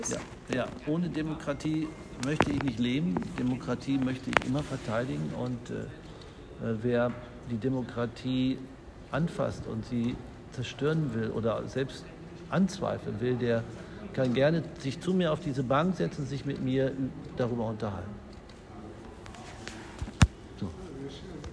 O-Ton von Peter Lohmeyer